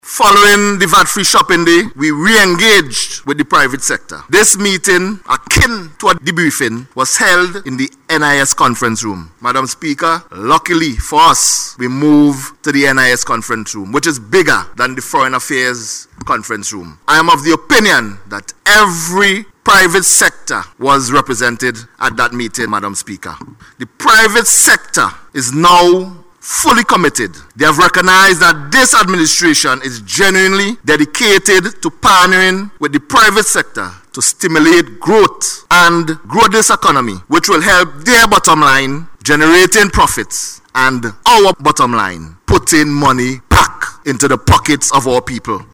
During his contribution to the Budget Debate, Minister Neptune said the state has been the primary employer of Vincentians over the years, and there must be a shift, if the country is to maximize the potential of its people and achieve real economic growth.